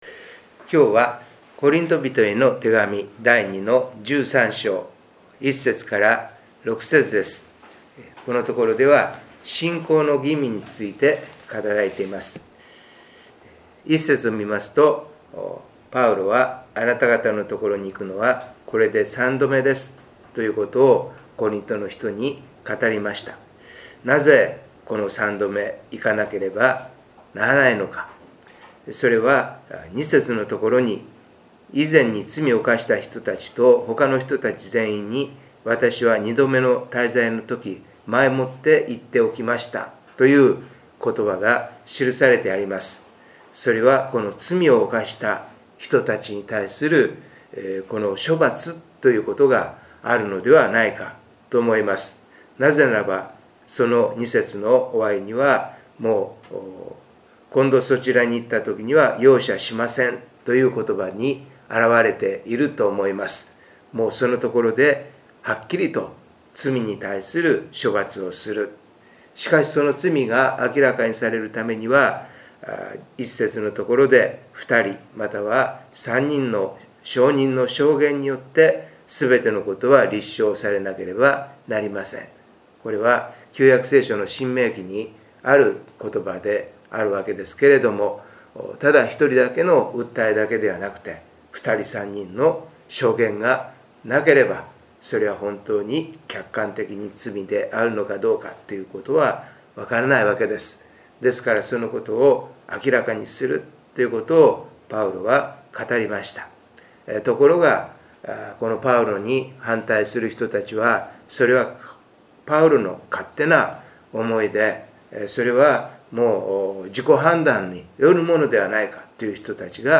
2023/8/9 聖書研究祈祷会